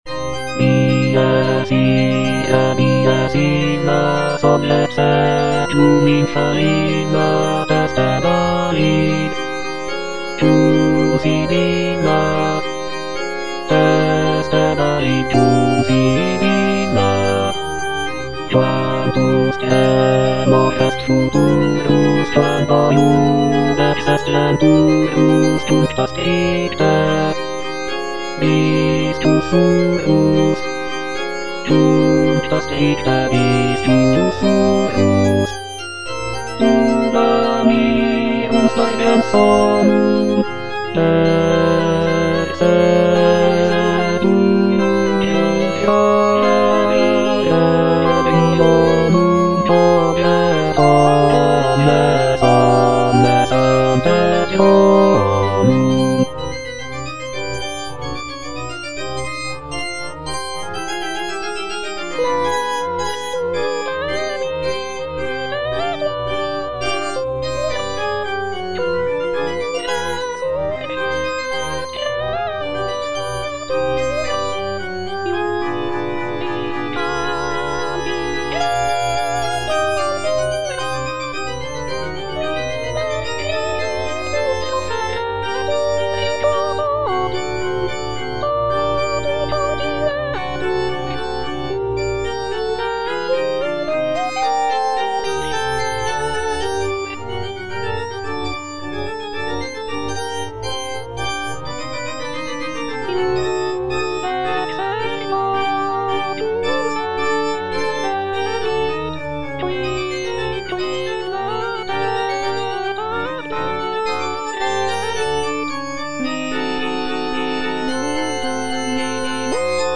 M. HAYDN - REQUIEM IN C (MISSA PRO DEFUNCTO ARCHIEPISCOPO SIGISMUNDO) MH155 Sequentia - Bass (Emphasised voice and other voices) Ads stop: auto-stop
The work is characterized by its somber and mournful tone, reflecting the solemnity of a funeral mass. Featuring beautiful choral harmonies and expressive melodies, Haydn's Requiem in C showcases his mastery of sacred music and ability to evoke deep emotional responses through his compositions.